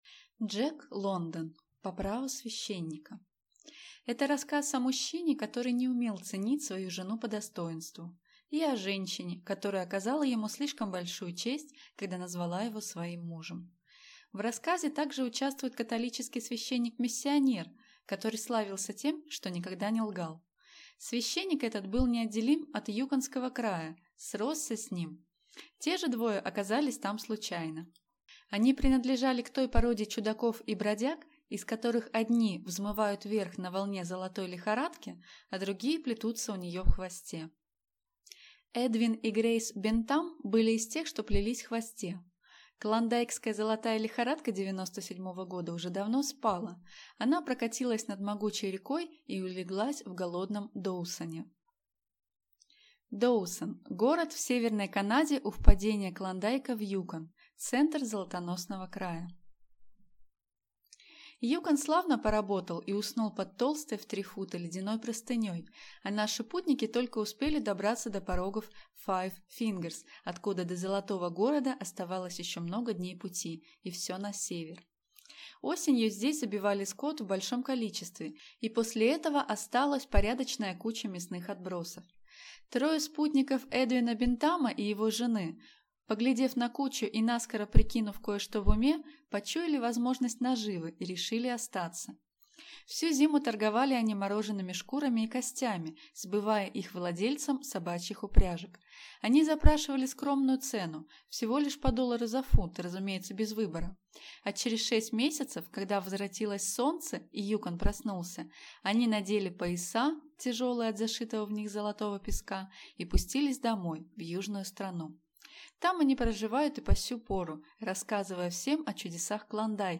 Aудиокнига По праву священника
Читает аудиокнигу